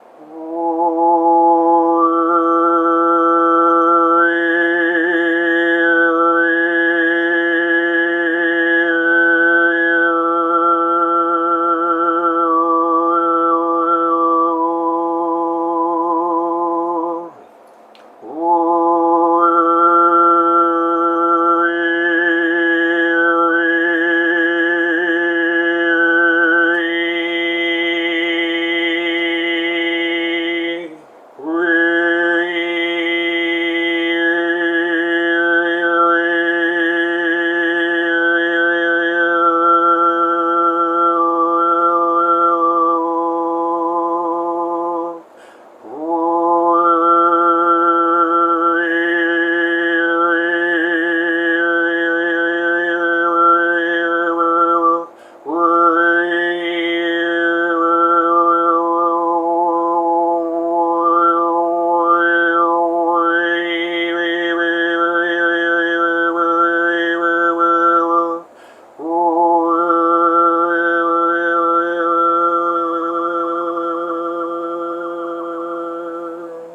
First of all, jump to 8:00 in the following video to hear some super cool overtone singing of “Amazing Grace”:
this slightly edited version I made that emphasizes the overtones slightly more.)
throat_singing.wav